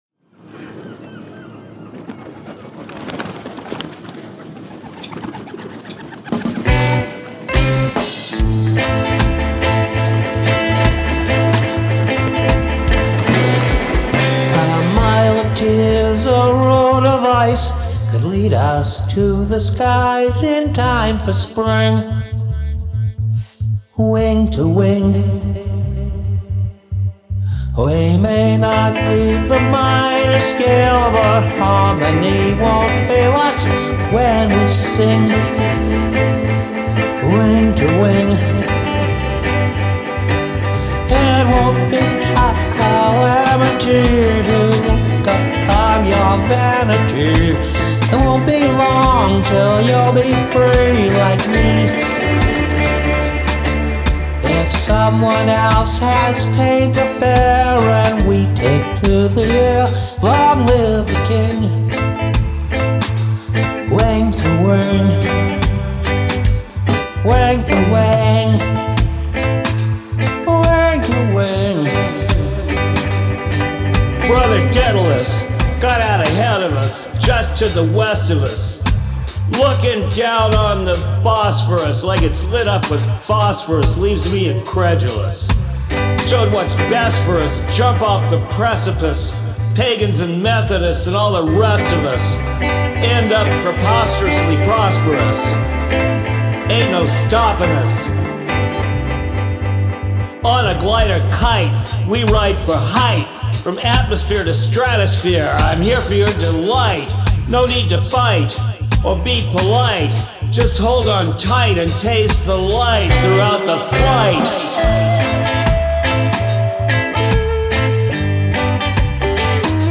DUB AUDIO VERSION (MP3)